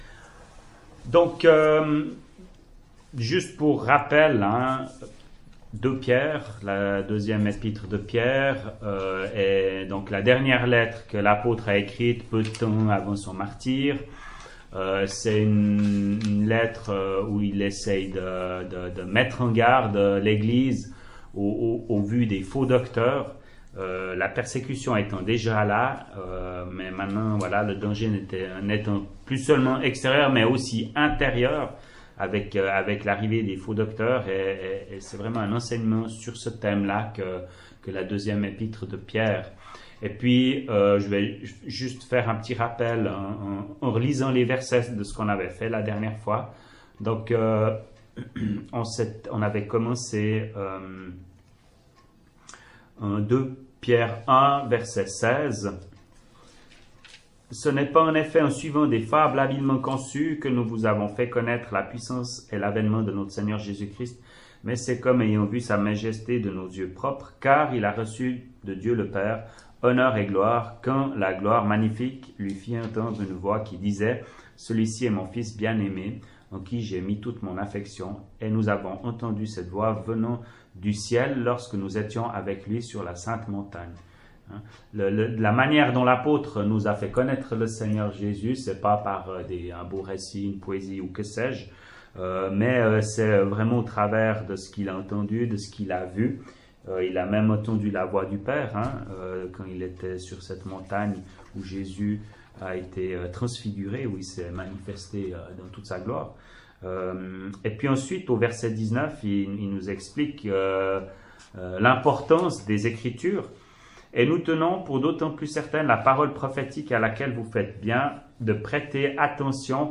[Chapelle de l’Espoir] - Étude biblique : Deuxième Épître de Pierre, 5ième partie
ÉTUDE BIBLIQUE : Evole, le 27.02.2019